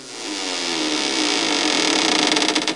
Attic Door Sound Effect
Download a high-quality attic door sound effect.
attic-door.mp3